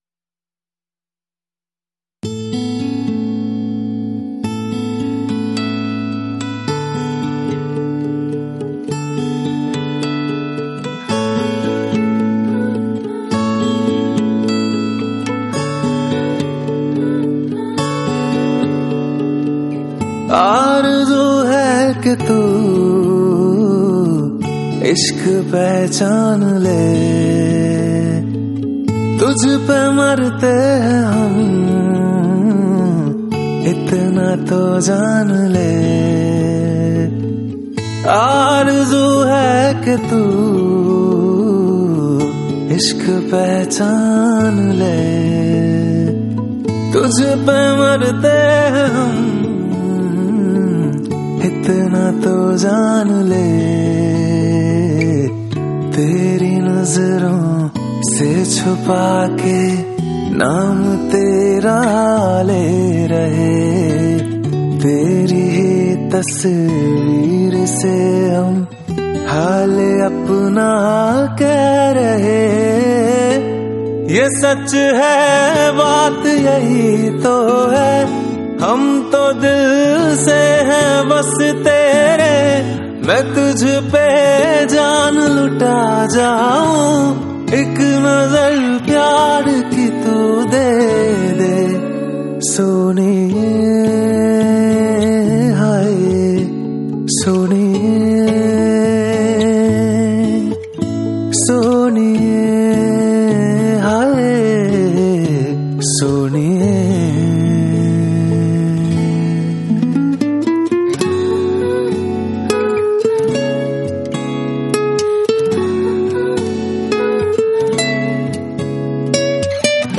Bollywood Mp3 Music 2016